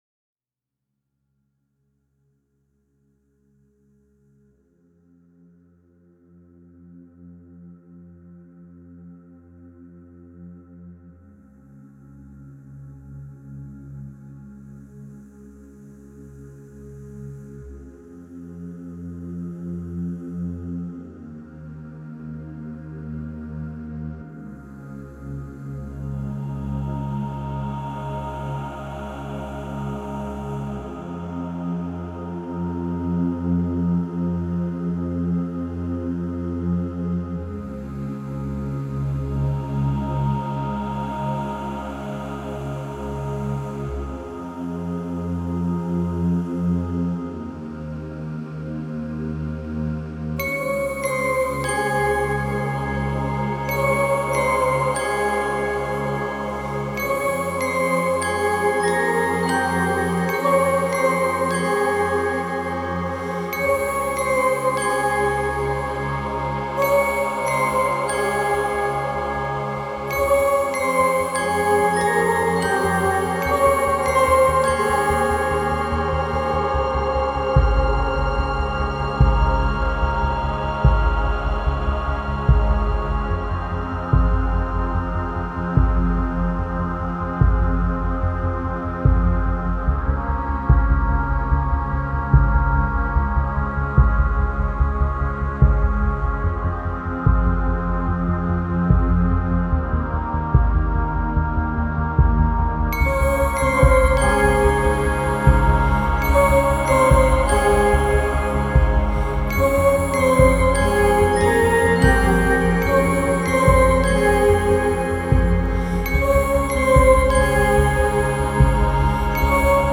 Genre : Score